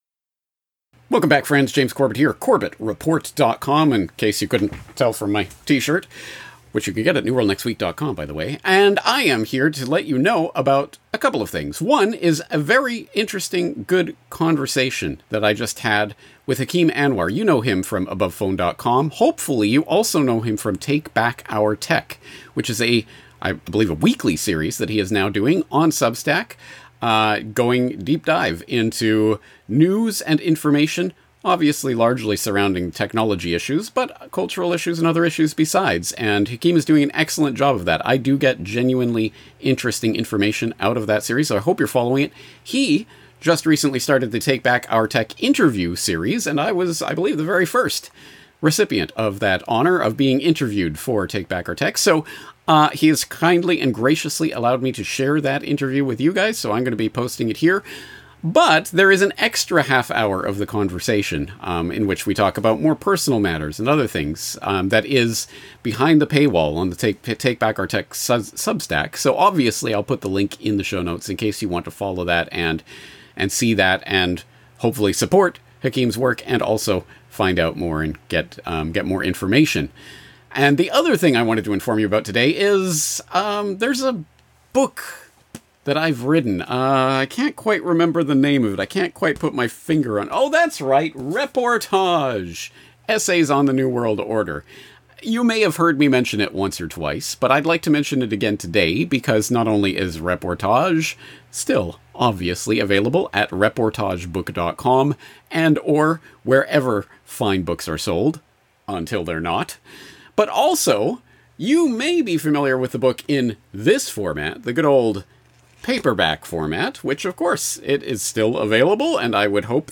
Issues covered include 9/11 and false flag terror, the Big Brother police state, the global warming hoax and how central banks control the political process. Guests include politicians, scientists, activists and newsmakers from around the world.